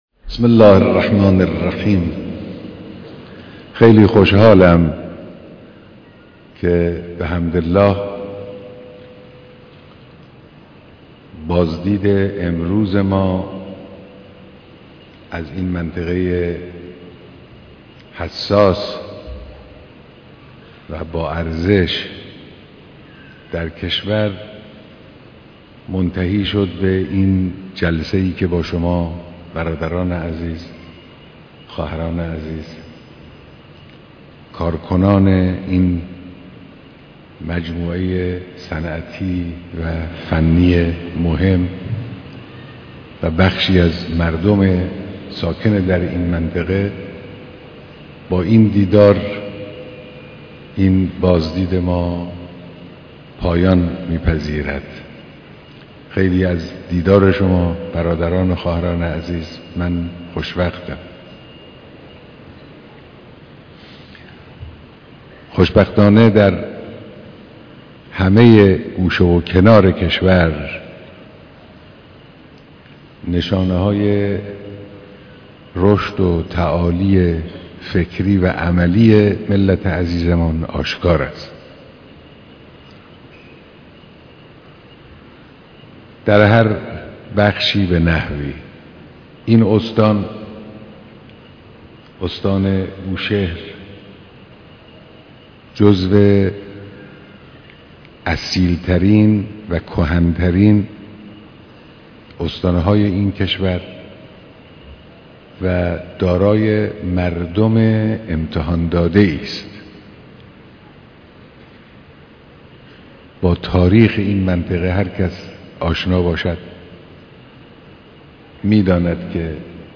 بیانات در جمع مهندسان و کارگران صنعت نفت